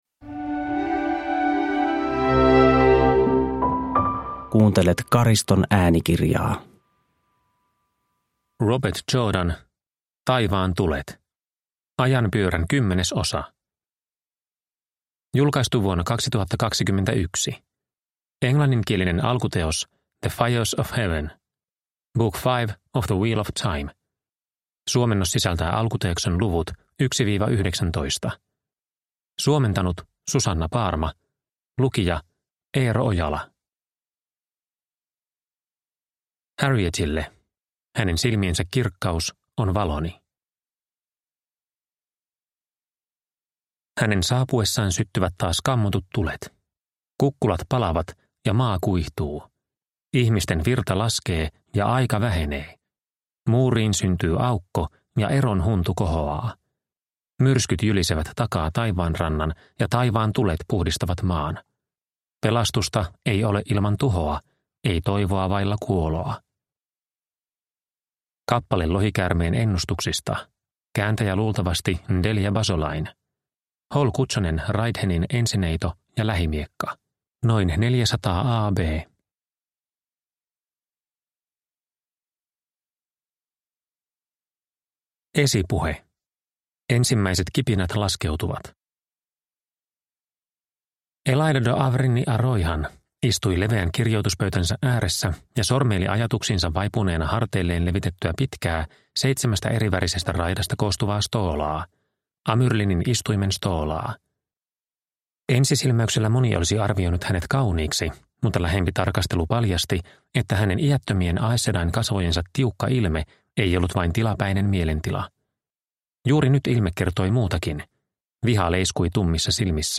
Taivaan tulet – Ljudbok – Laddas ner